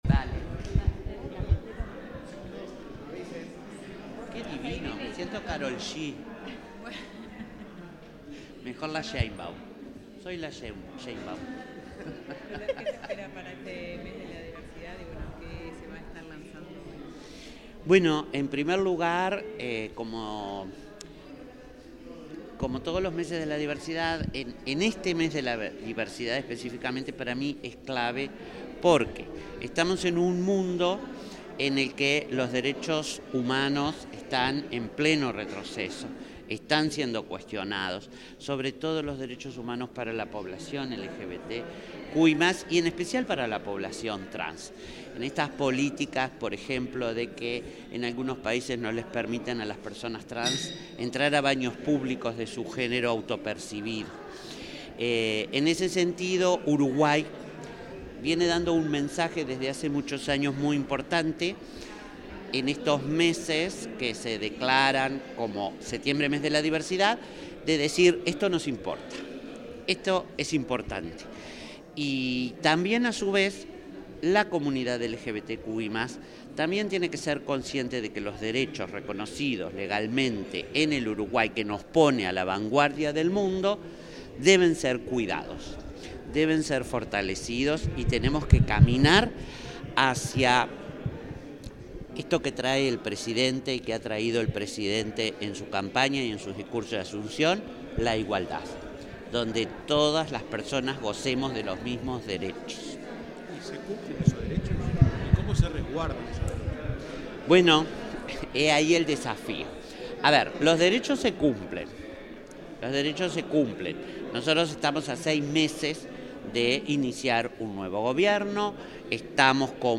Declaraciones de la titular de la Secretaría de Derechos Humanos de Presidencia, Colette Spinetti.
La titular de la Secretaría de Derechos Humanos de la Presidencia de la República, Colette Spinetti, efectuó declaraciones a la prensa en la apertura